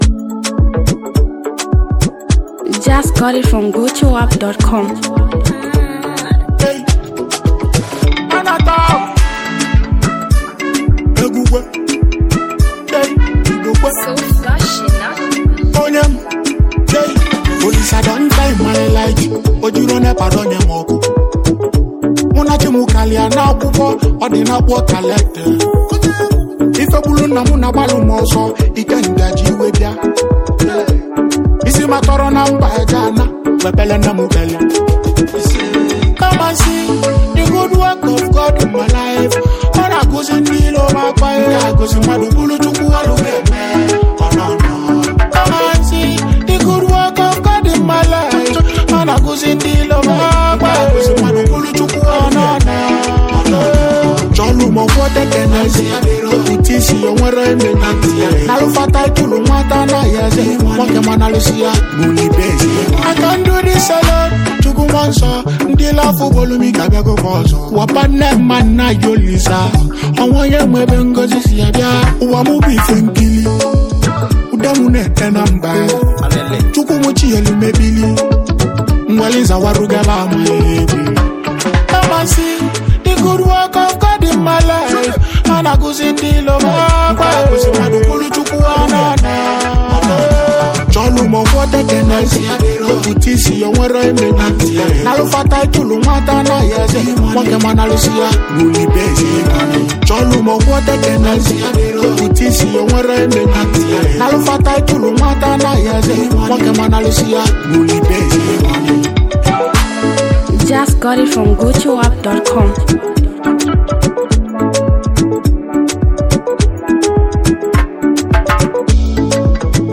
powerful gospelous hit sound